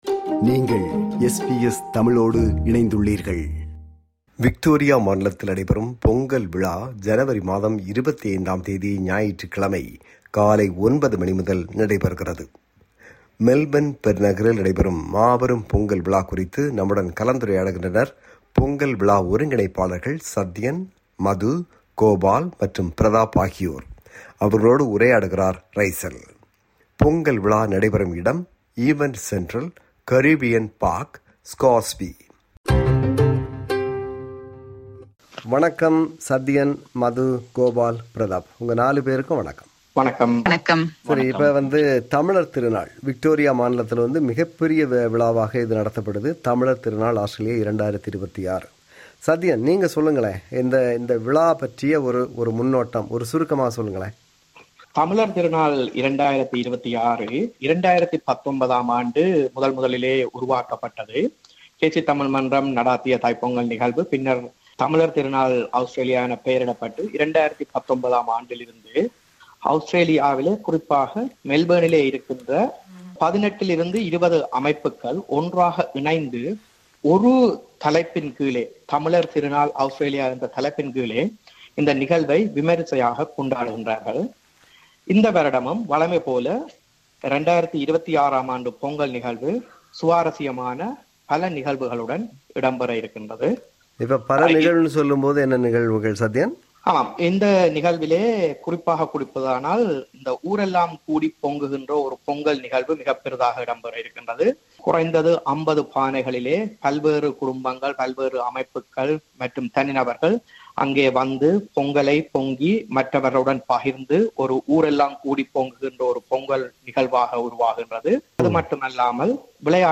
இருபத்தி ஏழு தமிழ் அமைப்புகள் இணைந்து மெல்பர்ன் பெருநகரில் நடத்தும் மாபெரும் பொங்கல் விழா குறித்து நம்முடன் கலந்துரையாடுகின்றனர் பொங்கல் விழா ஒருங்கிணைப்பாளர்கள்